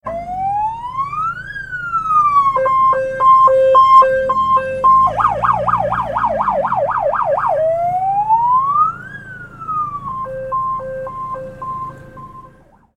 دانلود صدای ماشین پلیس 3 از ساعد نیوز با لینک مستقیم و کیفیت بالا
جلوه های صوتی